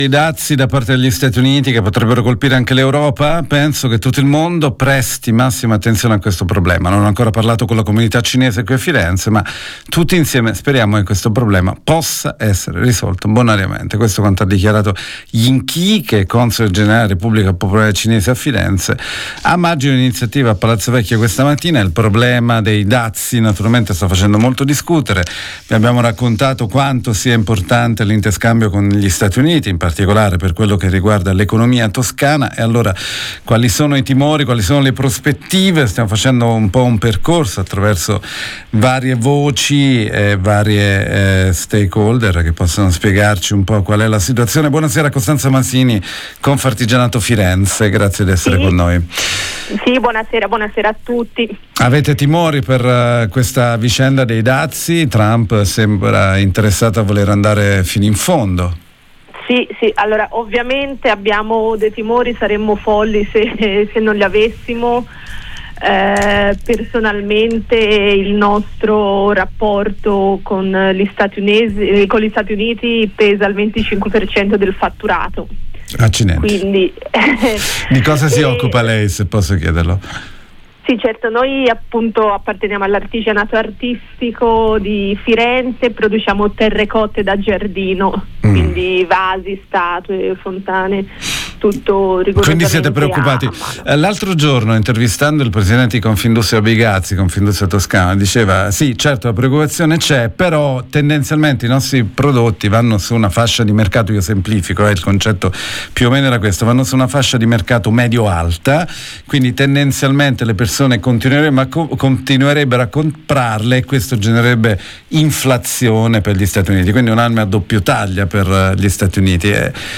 artigianato artisticoIntervista